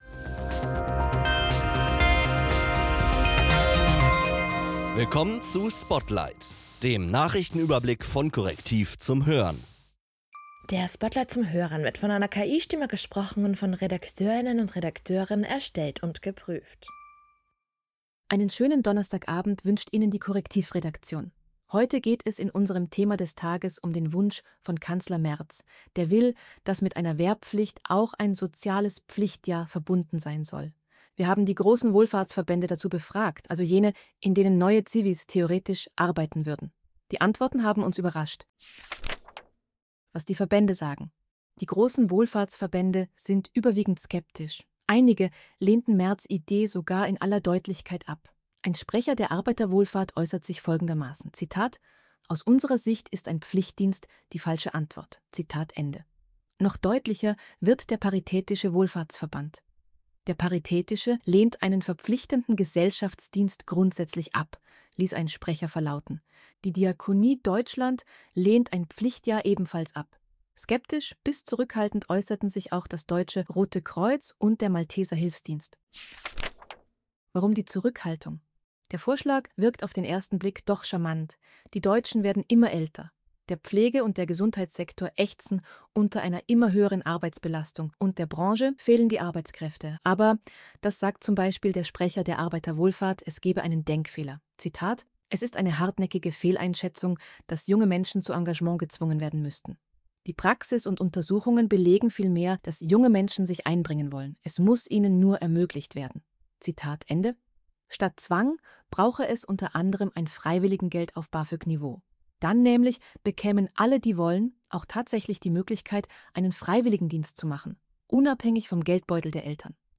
Diese für Audio optimierte Kompaktfassung des täglichen Spotlight-Newsletters ist von einer KI-Stimme eingelesen und von Redakteuren erstellt und geprüft.